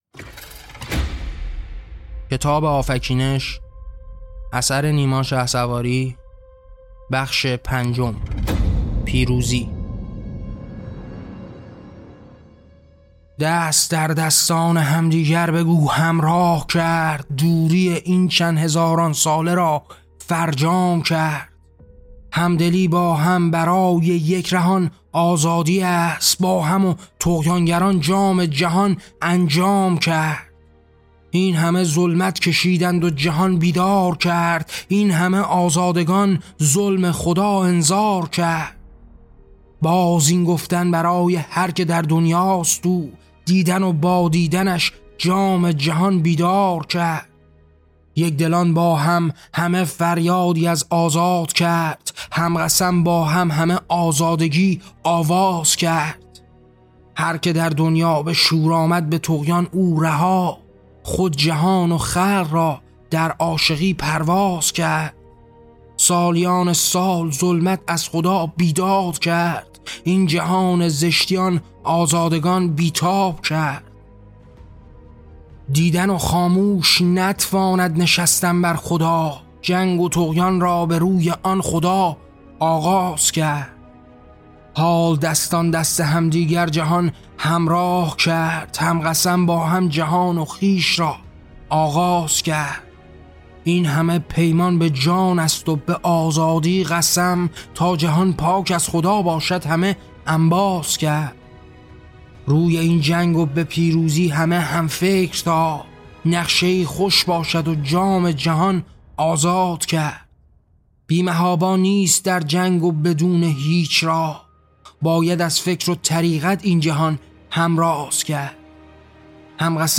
کتاب صوتی «آفکینش»
این نسخه شنیداری با کیفیت استودیویی جهت غوطه‌وری کامل در مفاهیم اثر تهیه شده است.